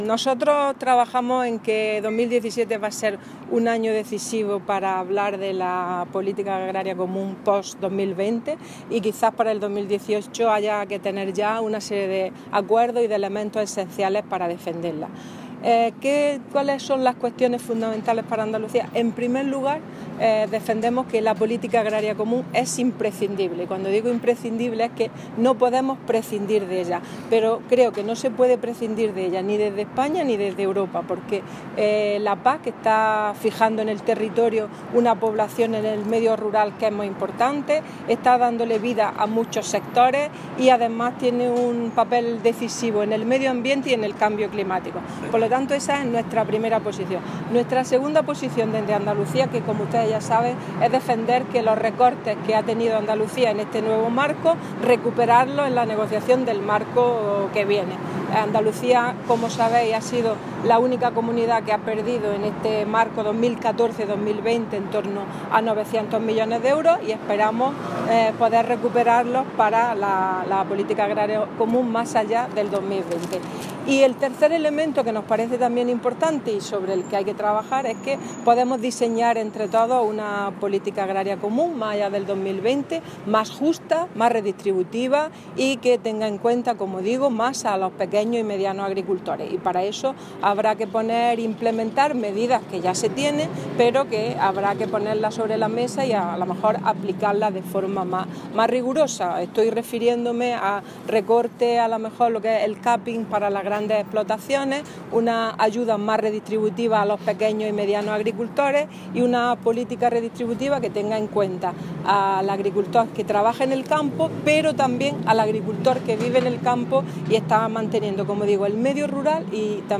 Declaraciones de Carmen Ortiz sobre PAC